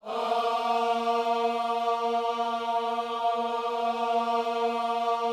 OHS A#3D.wav